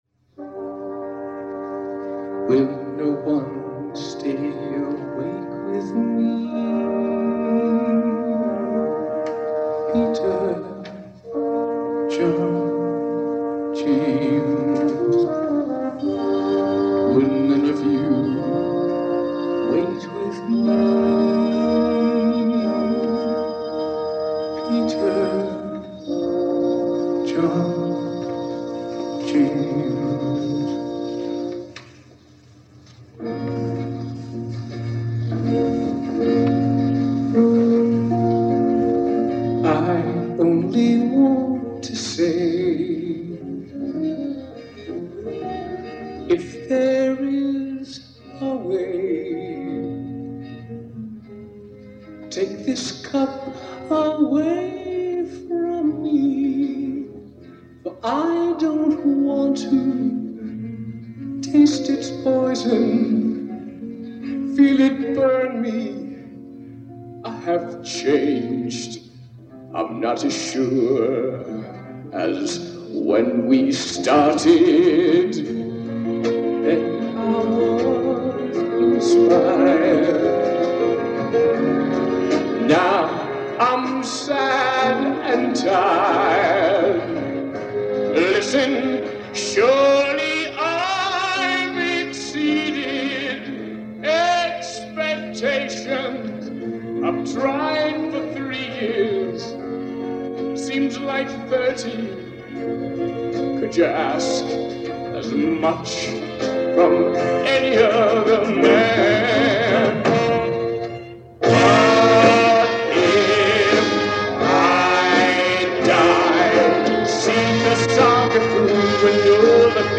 It must’ve been recorded on a small cassette player sitting on the persons lap that sent me the cassette.
It was a rock band and a full orchestra in a big theater. And to my surprise it was a better quality than it should’ve been.